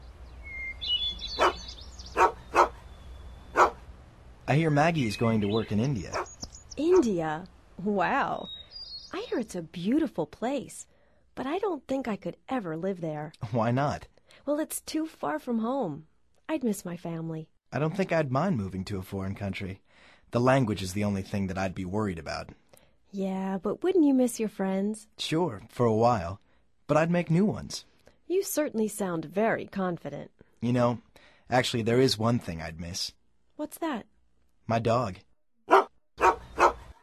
Escucha el audio y concéntrate en la entonación y ritmo de las frases.